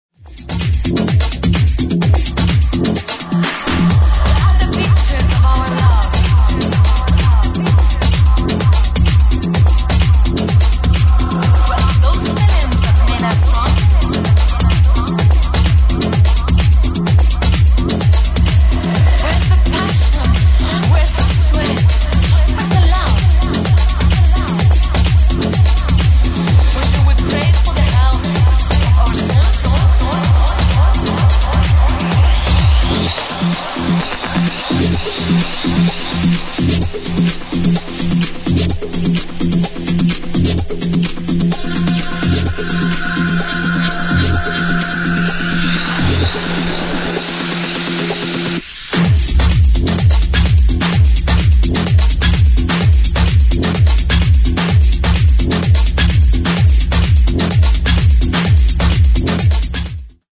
i heard it on radio